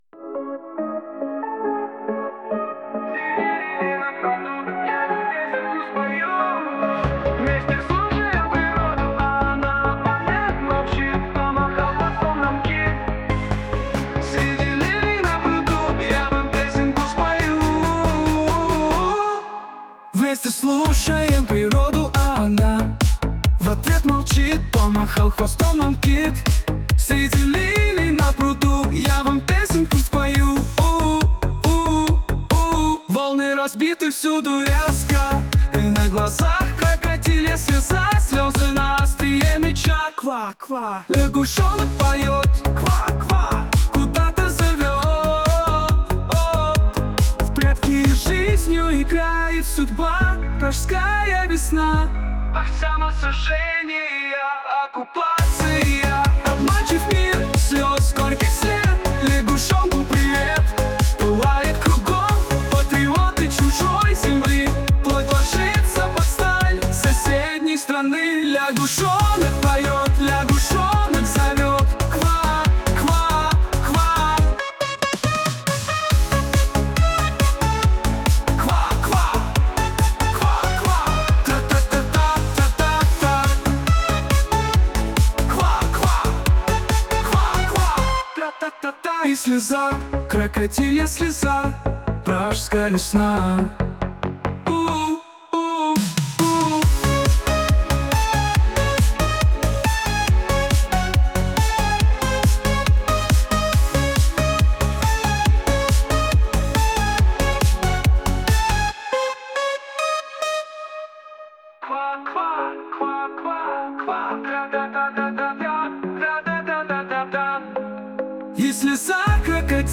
12 12 16 sp 021 А музика приз- аж танцювати охота,хоч сьогодні не субота.